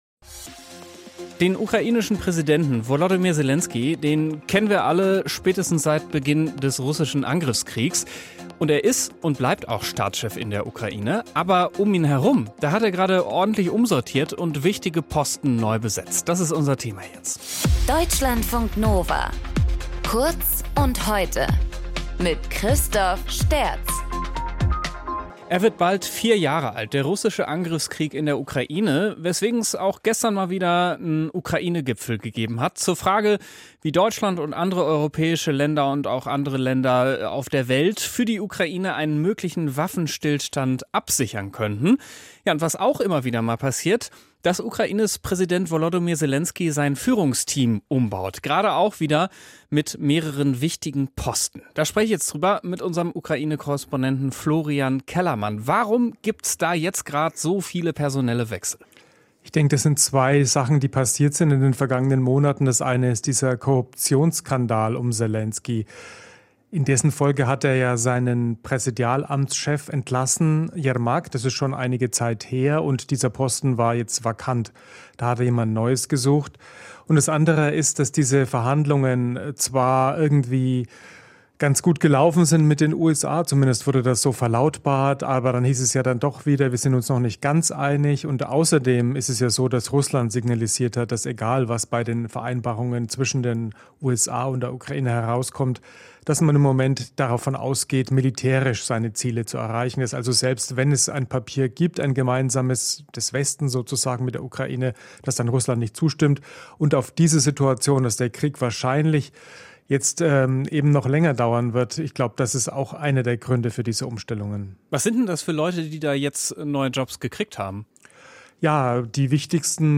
Moderator: